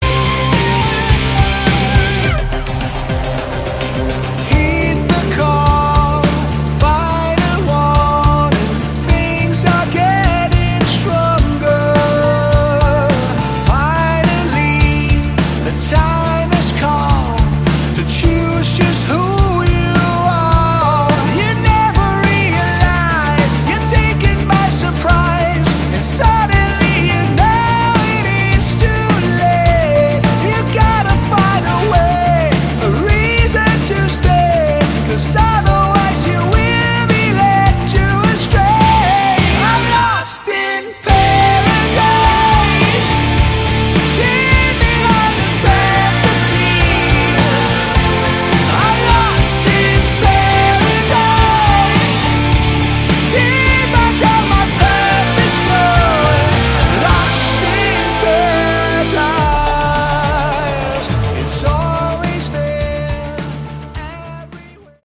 Category: Hard Rock
lead vocals
bass, vocals, rhythm guitars
drums
keyboards
lead guitars